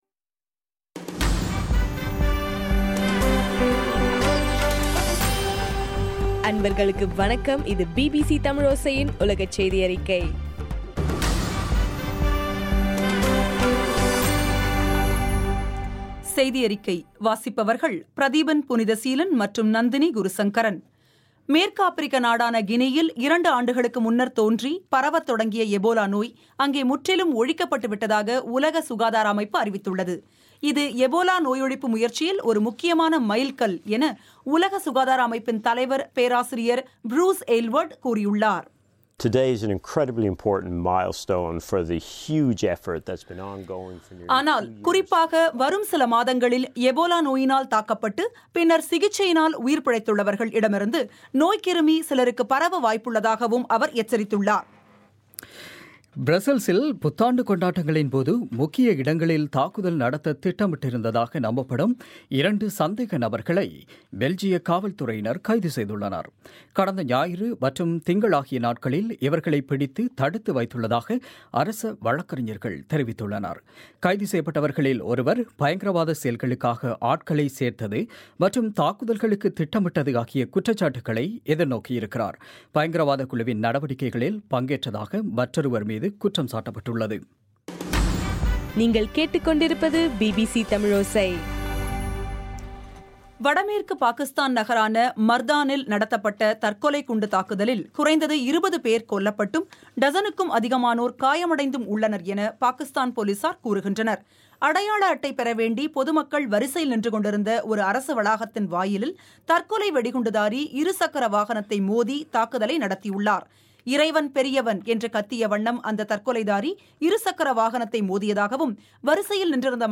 டிசம்பர் 29, 2015 பிபிசி தமிழோசையின் உலகச் செய்திகள்